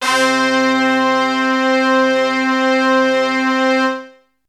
Brass 1.wav